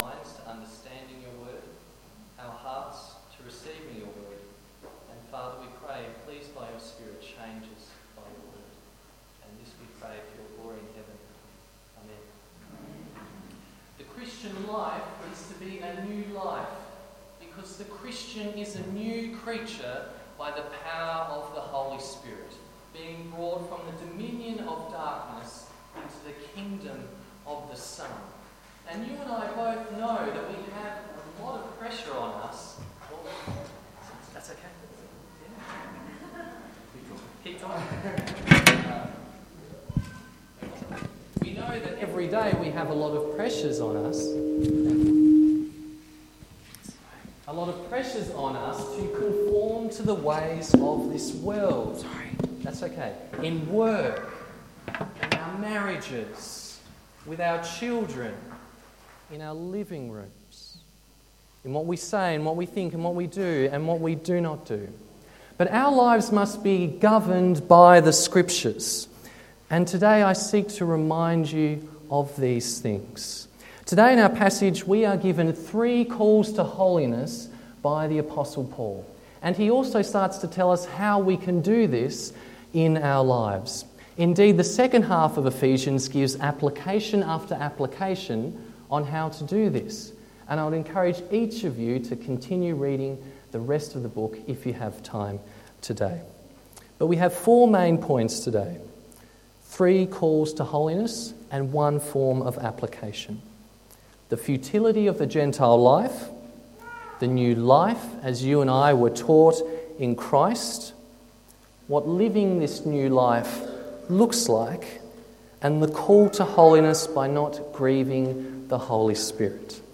Ephesians 4:17-32 Sermon